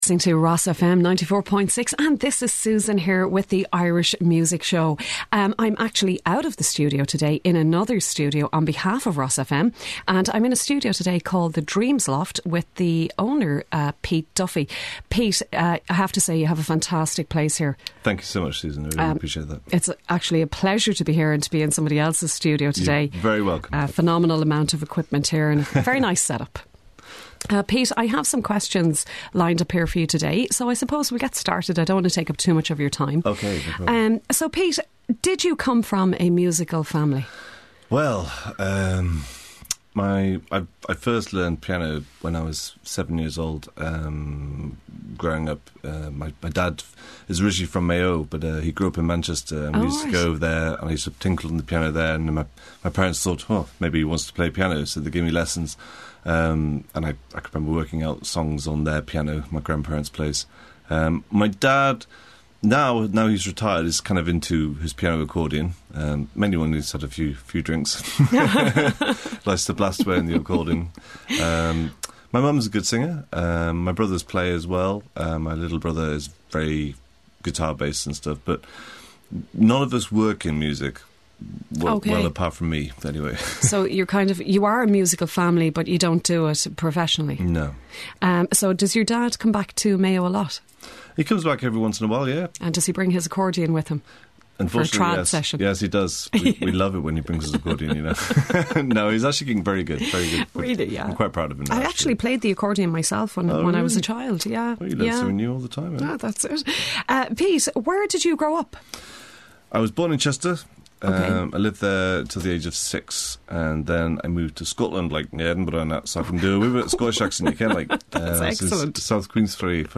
Interview - RosFM 94.6